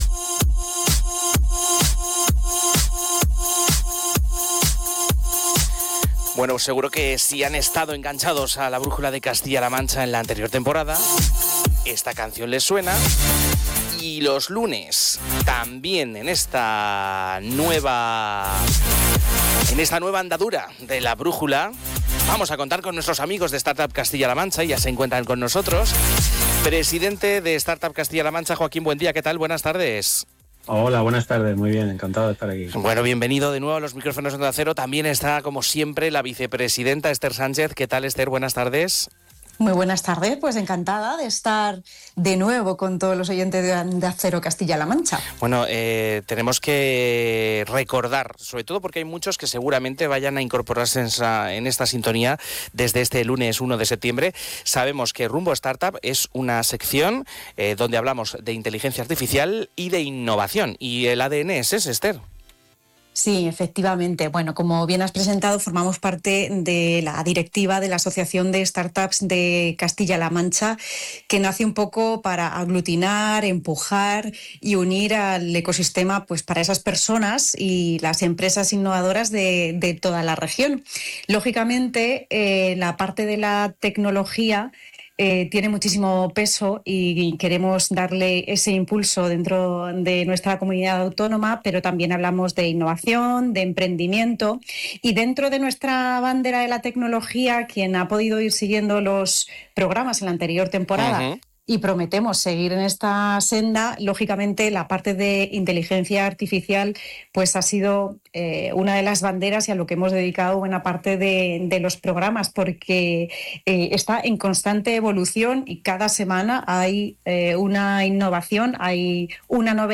En la primera temporada, se atrevieron a pedirle a ChatGPT que inventara el nombre de la sección y fueron más allá al utilizar Suno para que la música original del programa viniera desde las manos de una inteligencia artificial.
Pues mándales tu petición, que seguro que en el próximo programa lo solucionan con la chispa y el estilo desenfadado que les caracteriza.